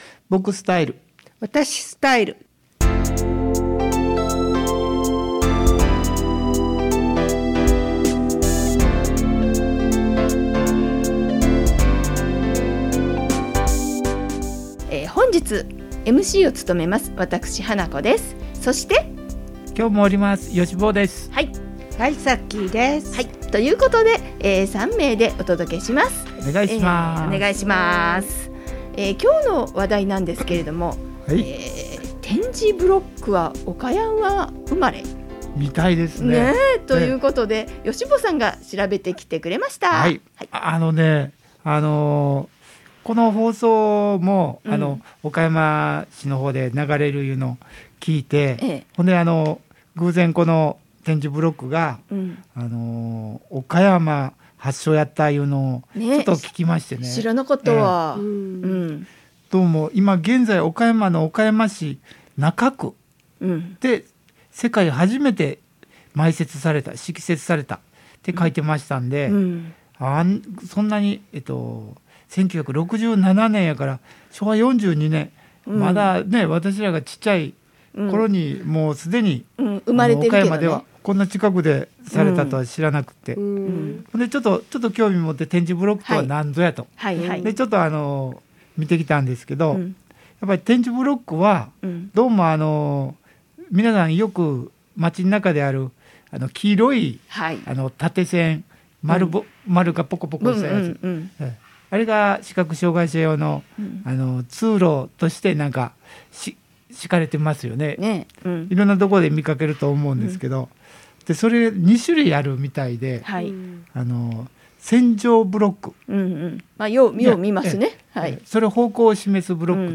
※2022年にコミュニティFMで放送された番組を再配信いたします。
場所：相生市総合福祉会館